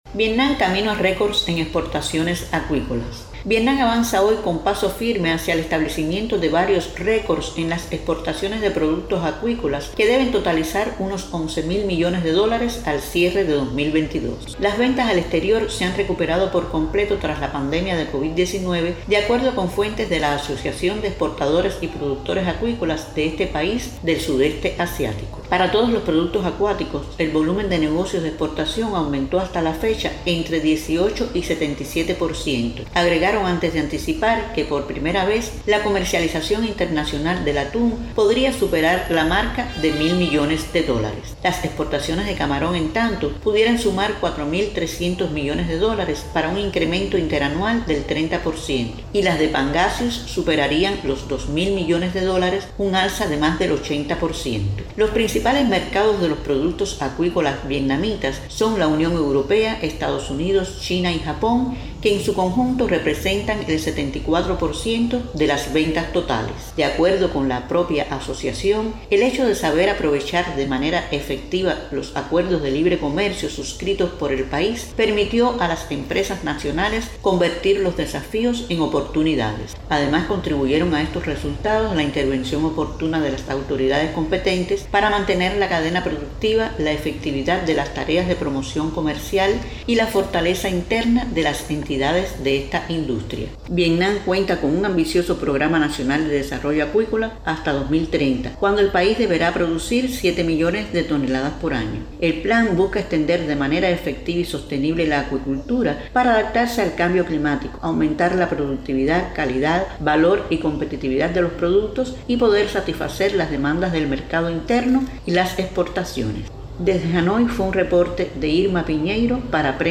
desde Hanoi